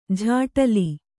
♪ jhāṭali